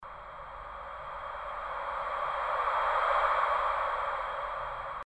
Rauschen von Meereswellen
myHummy-ocean-wves.mp3